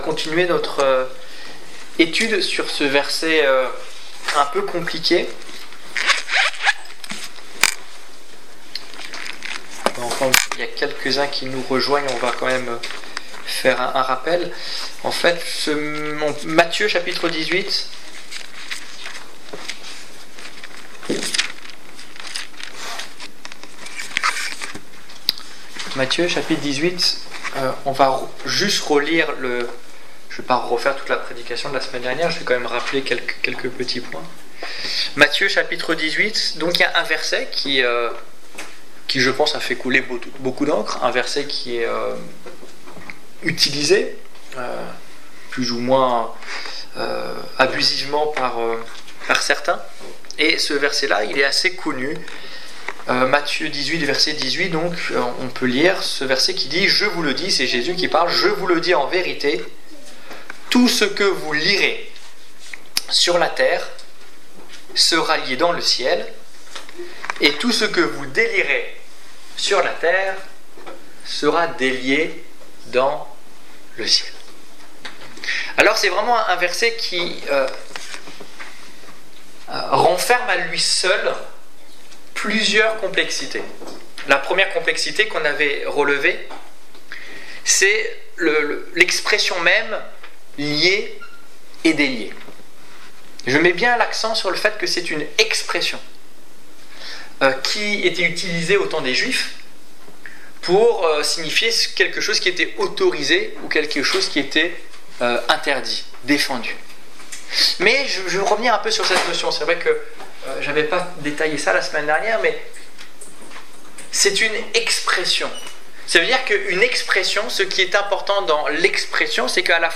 Étude biblique du 14 septembre 2016